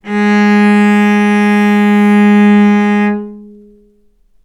vc-G#3-ff.AIF